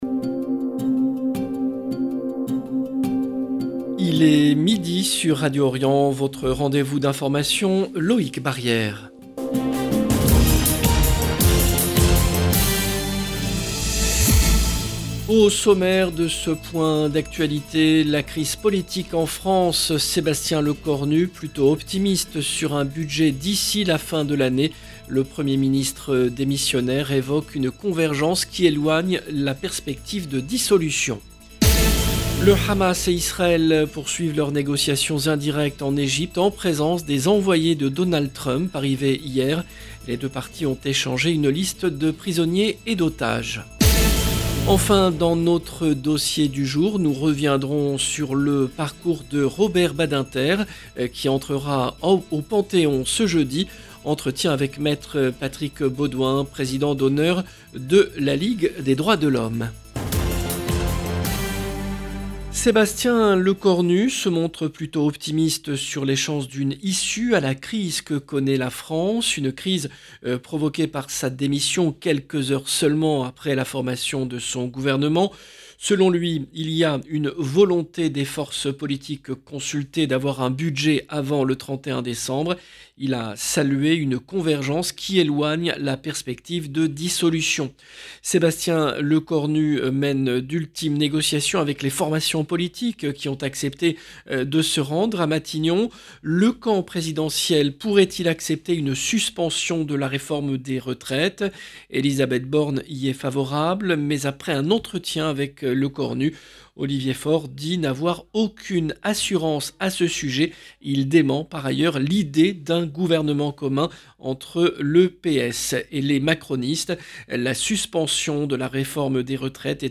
Journal de midi